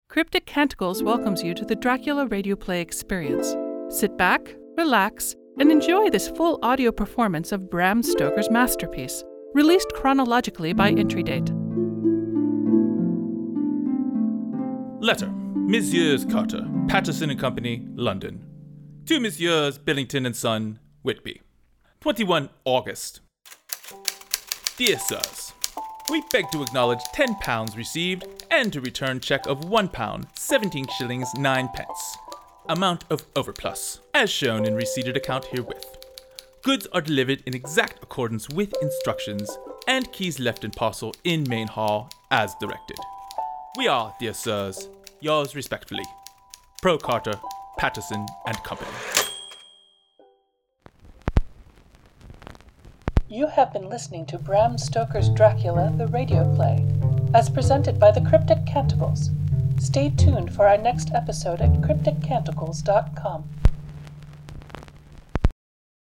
Audio Engineer, SFX and Music